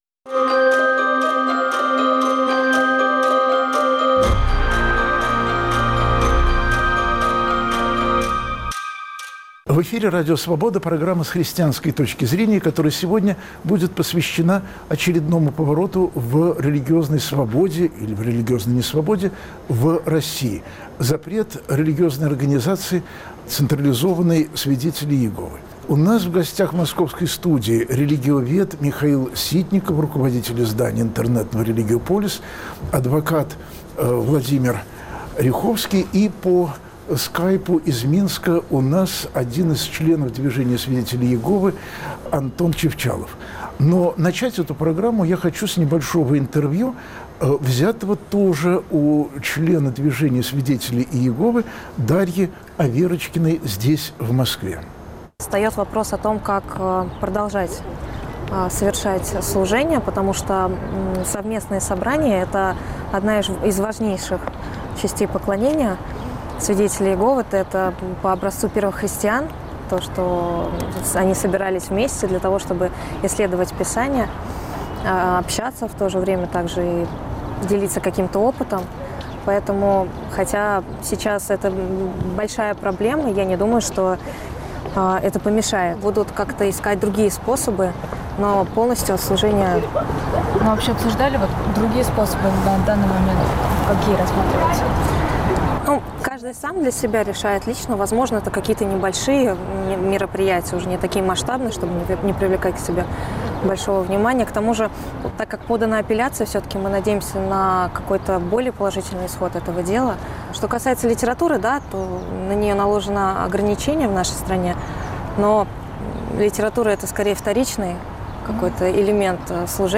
Есть ли в России свобода совести: спорят религиовед, адвокат и сторонник запрещенной религиозной организации